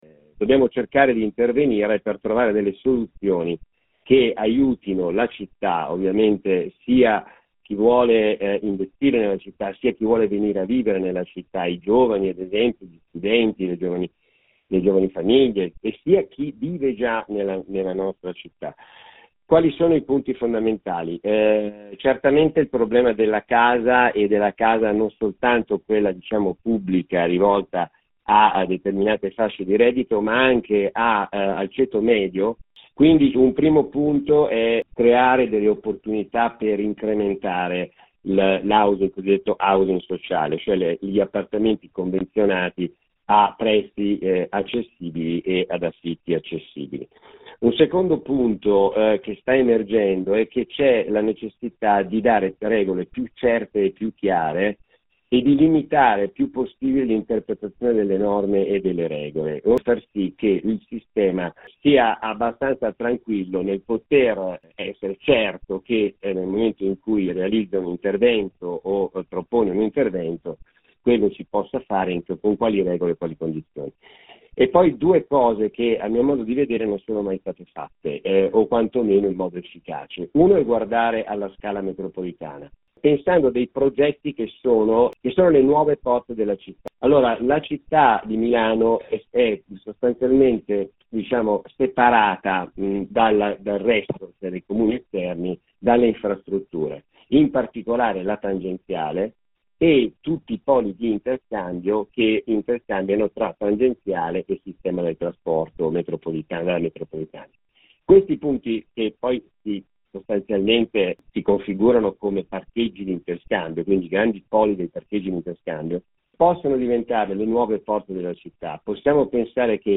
Il Comune o meglio la giunta nella persona del suo assessore alla Rigenerazione urbana (ex urbanistica) Giancarlo Tancredi ha avanzato ai nostri microfoni e poi nel forum dedicato al futuro della città la sua proposta (che potete sentire nell’intervista completa qua sotto) e che possiamo tradurre in 20mila alloggi in dieci anni alle Nuove Porte di Milano, nelle aree dei parcheggi di interscambio ferrovia-tangenziali come Bisceglie, Molino Dorino, Rogoredo, Cascina Gobba e così via. L’assessore parla di “densificazione” e di portare funzioni urbane e nomina come principali interlocutori del progetto “le cooperative”.